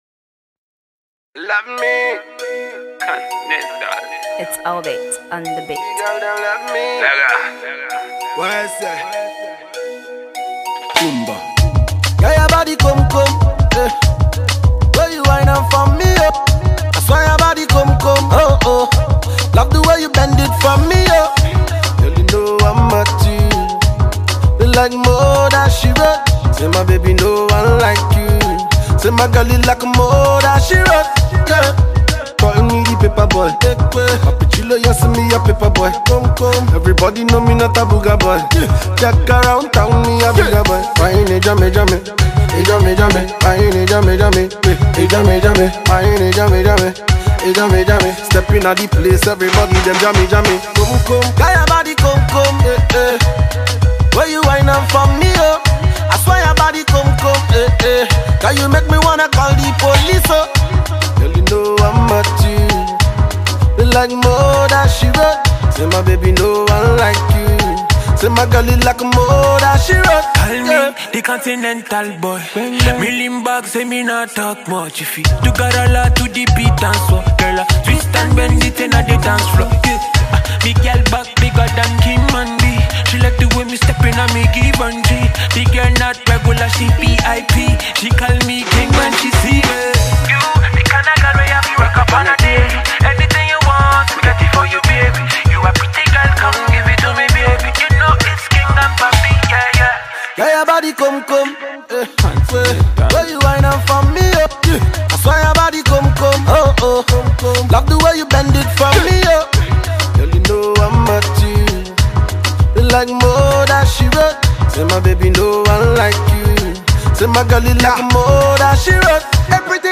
dance song
dancehall tune